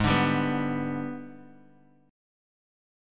G#mM7b5 chord